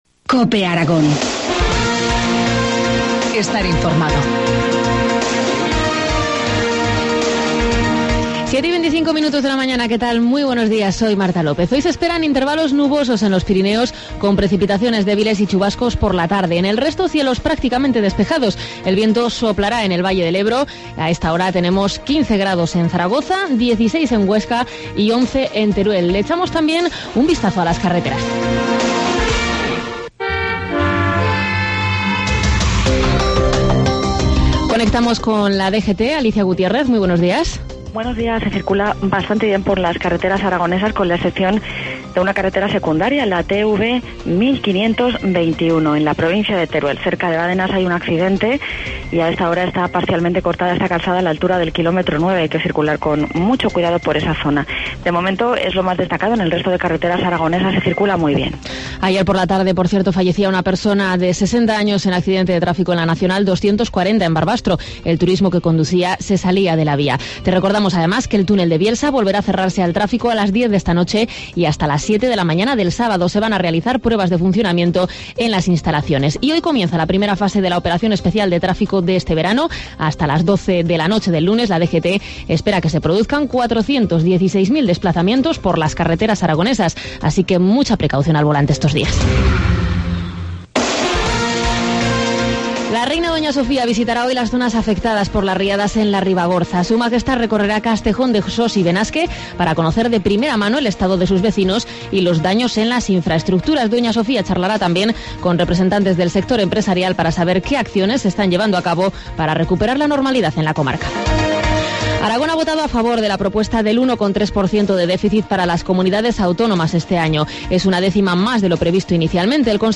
Informativo matinal,, 28 junio, 7,25 horas